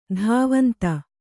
♪ dhāvanta